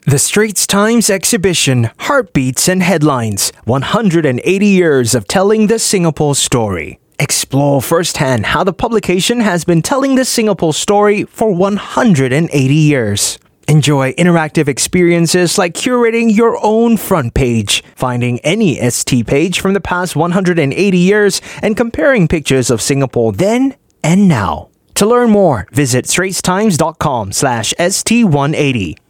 Voice Samples: Straits Times 180 (Clean VO)
male